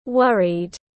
Lo lắng tiếng anh gọi là worried, phiên âm tiếng anh đọc là /’wʌrid/